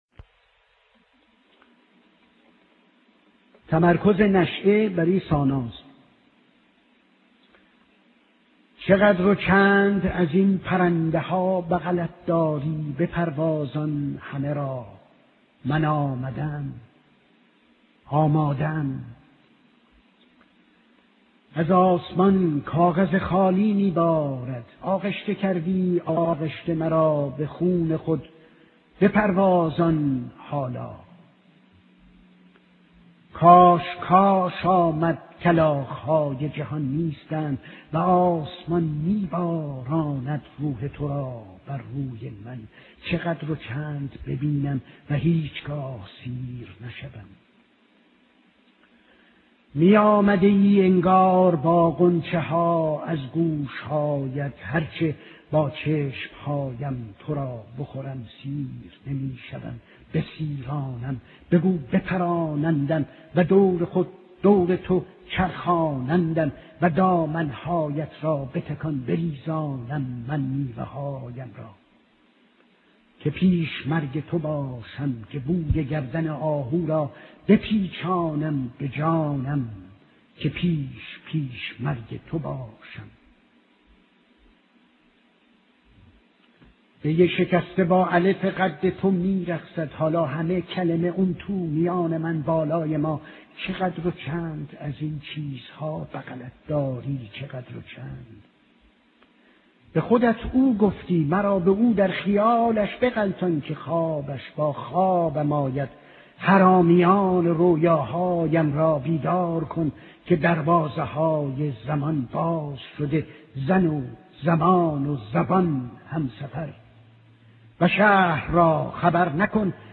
رضا براهنی. تمرکز نشئه (به همراه ِ صدای شاعر)
صدای شاعر